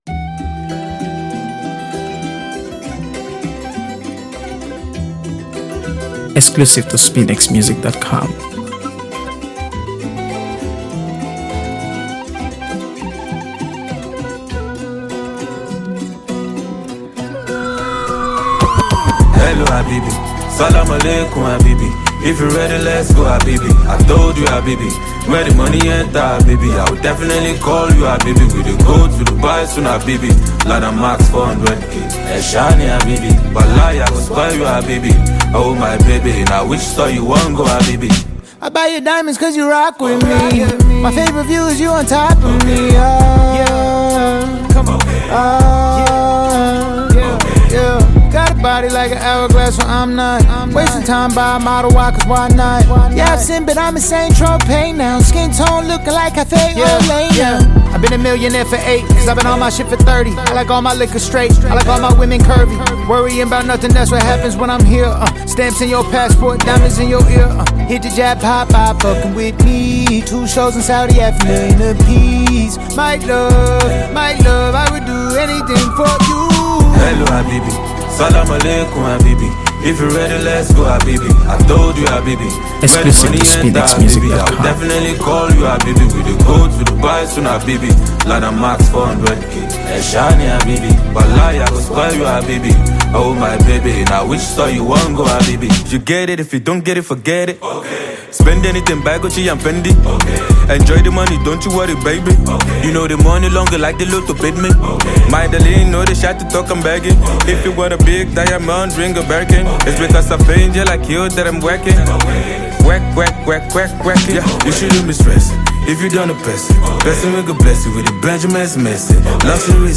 AfroBeats | AfroBeats songs
With its evocative Arabic-inspired instrumental
delivers a smooth, catchy verse